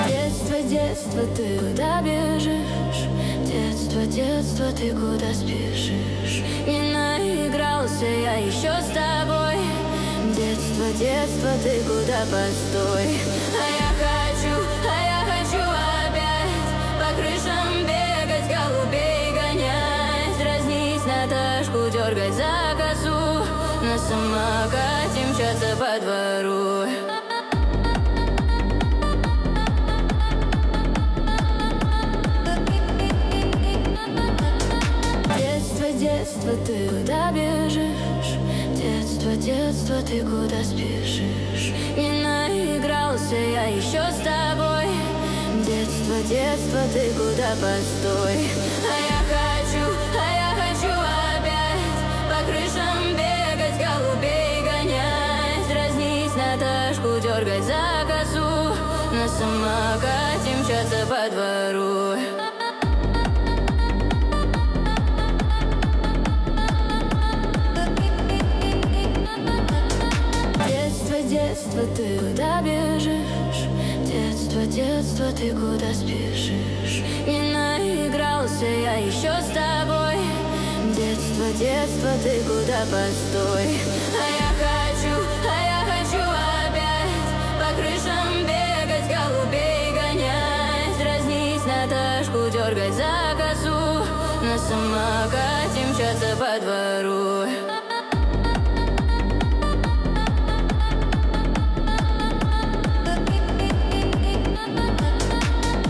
Качество: 320 kbps, stereo
Ремиксы